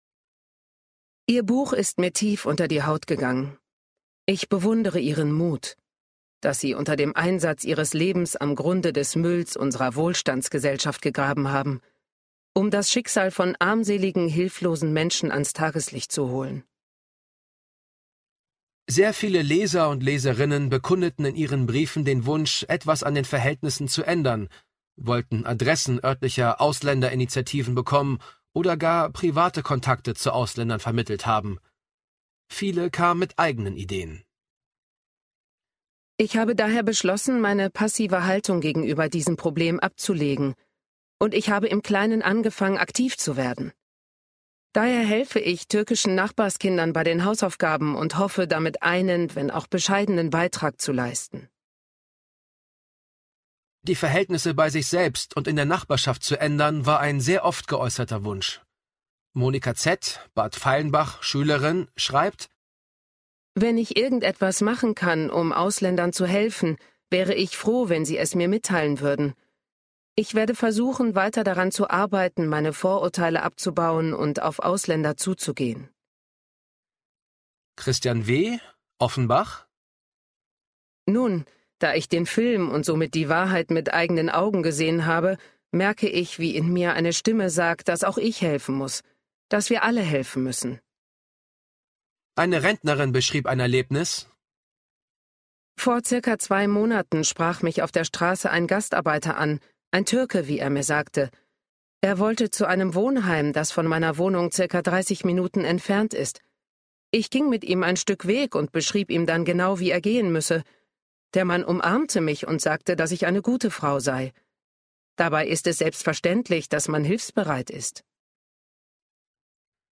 Sprecherin, Werbesprecherin
Dokumentation - ARTE VoiceOver 1
Hörbuch